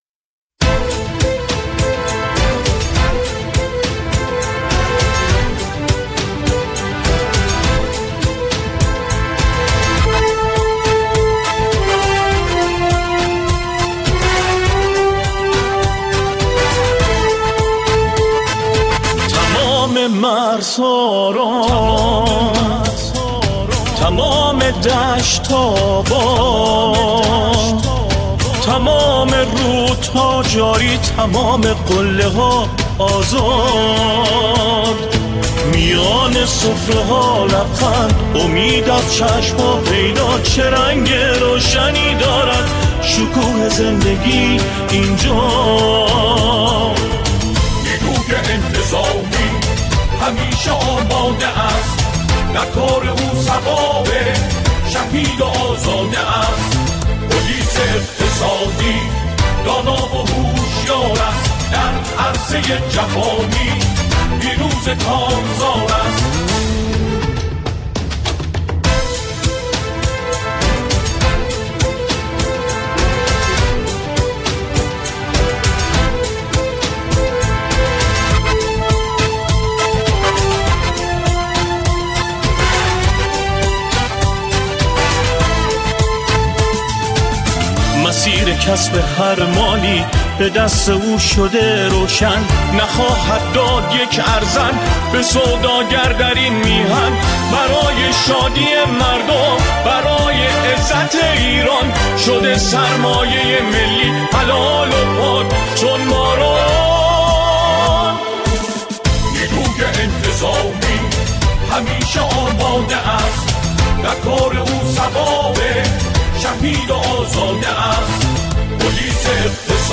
قطعه